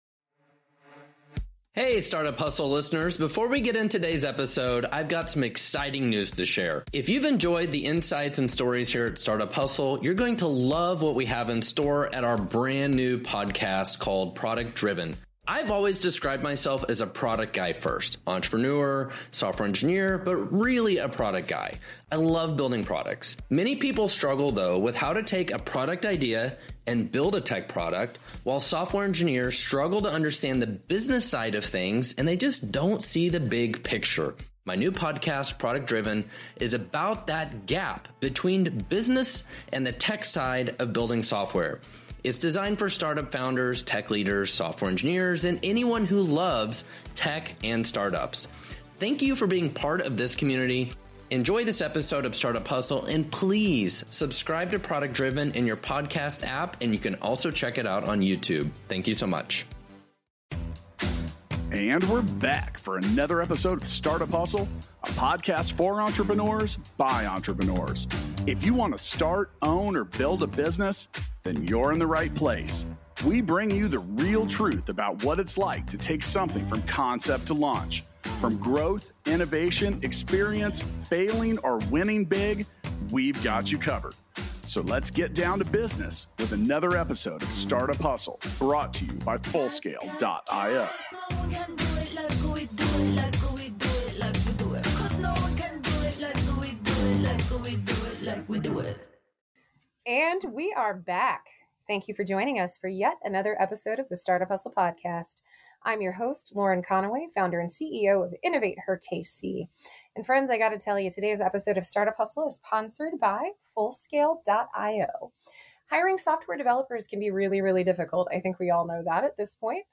for a conversation regarding the high cost of turnover. Hear great insights on managing your team, professional training, career development strategies, and how to recognize the great work being produced by your employees.